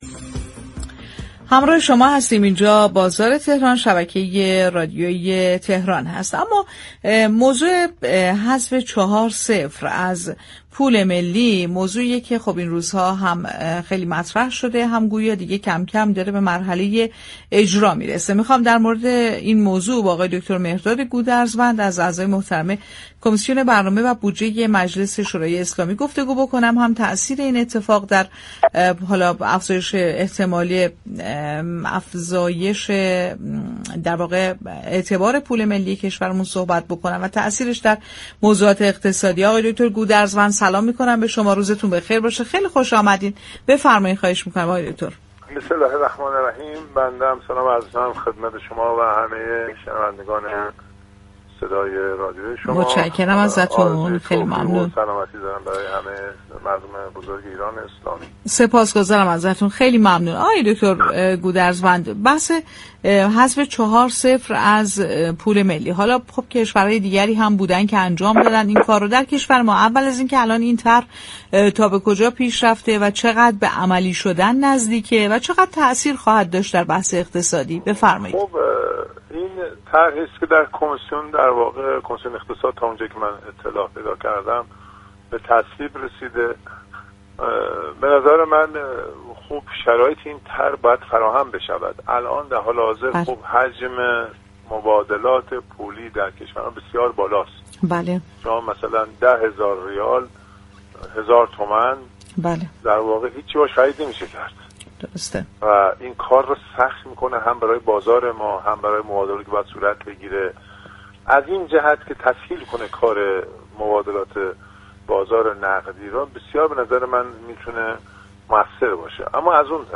مهرداد گودرزوند، نماینده مجلس و عضو كمیسیون برنامه و بودجه، در گفت‌وگو با رادیو تهران گفت: حذف چهار صفر از پول ملی ایران در كمیسیون اقتصادی تصویب شده، اما این طرح بدون كنترل بازار و ثبات اقتصادی، تأثیر واقعی بر ارزش ریال نخواهد داشت.